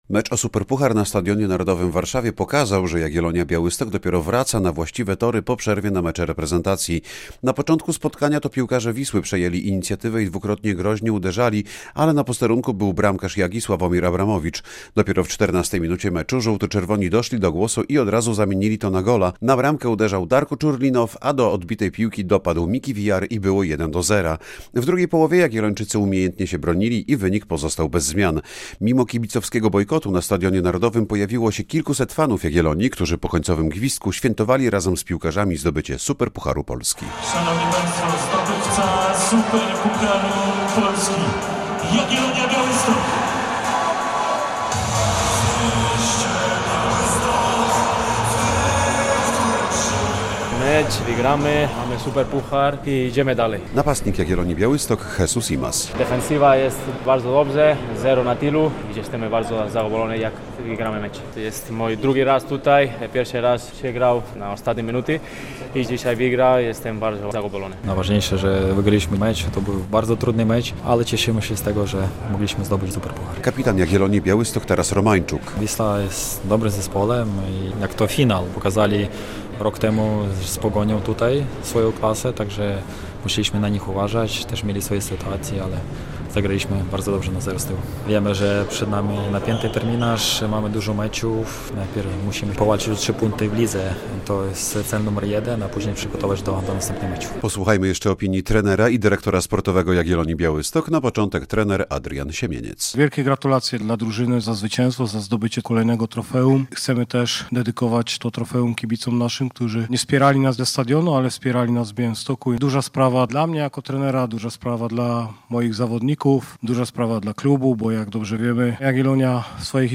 Duma Podlasia z Superpucharem Polski - relacja